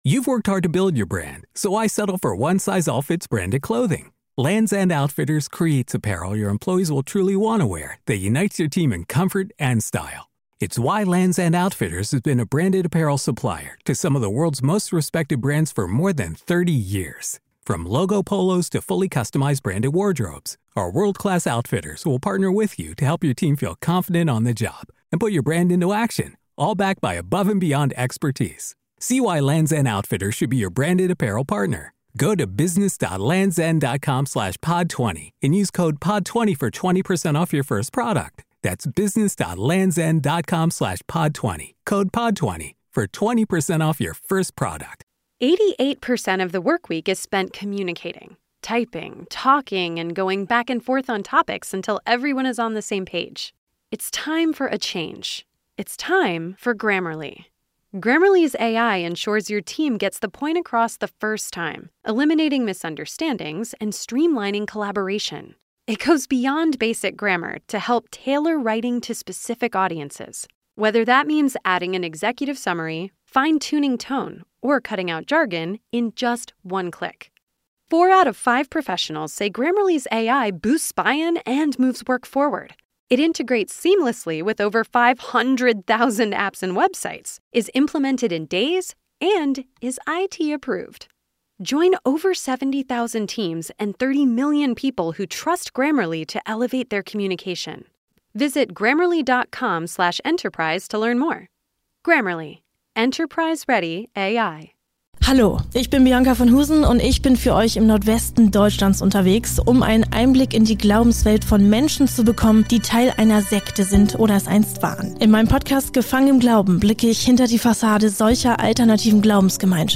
der tägliche News-Podcast aus dem Norden